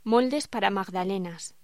Locución: Moldes para magdalenas